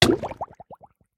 Minecraft Version Minecraft Version snapshot Latest Release | Latest Snapshot snapshot / assets / minecraft / sounds / entity / player / hurt / drown4.ogg Compare With Compare With Latest Release | Latest Snapshot
drown4.ogg